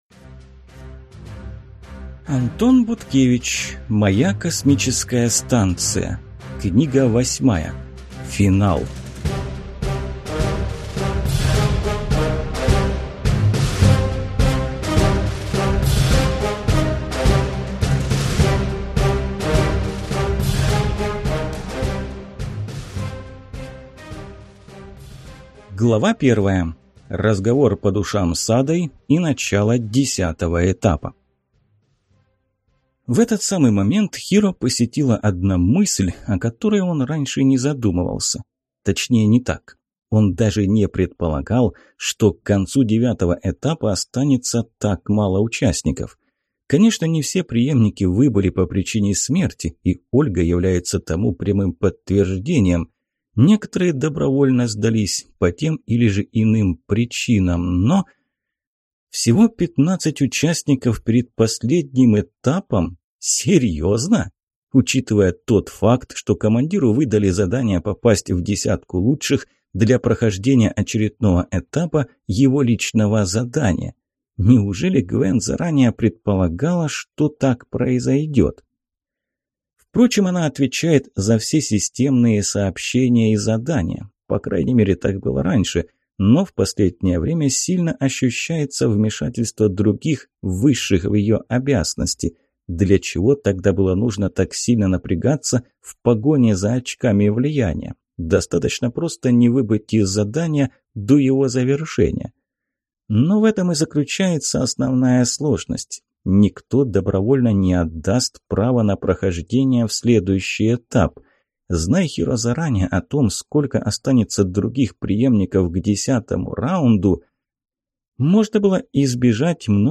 Аудиокнига Моя Космическая Станция. Книга 8. Финал | Библиотека аудиокниг